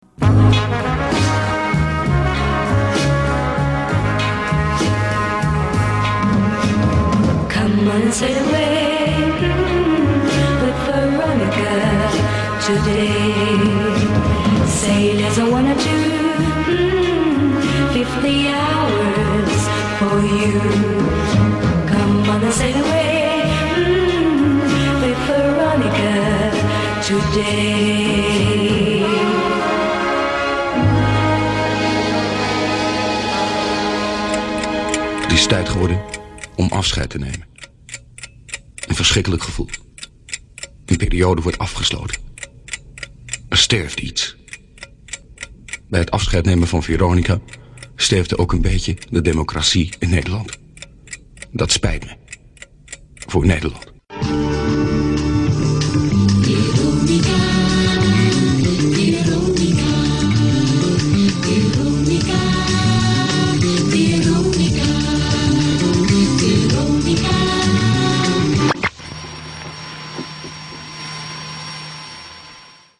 To today's radio listeners, flattered with huge choice from around the World, in digital quality, it's probably puzzling that anyone should care about a medium wave radio station crackling into the UK in a foreign language.